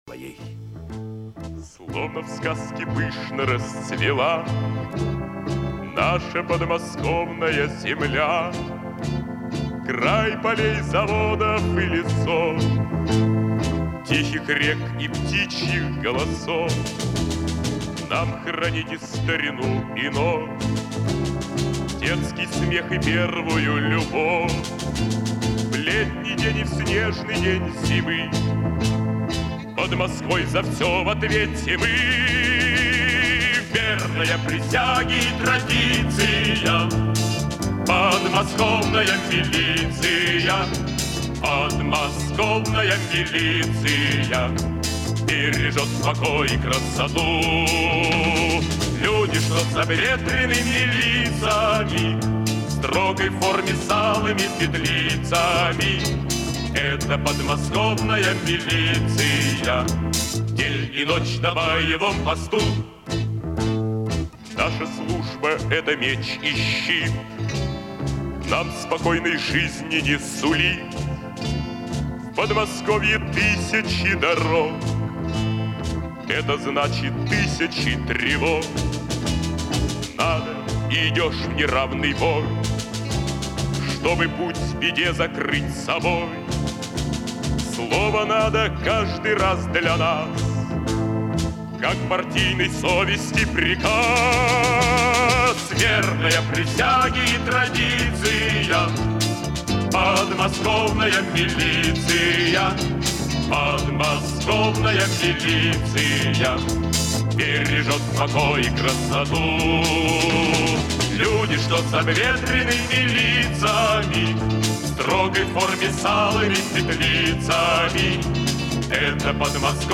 Какое-то слово слышно.
Стихи звучат и всё слитно.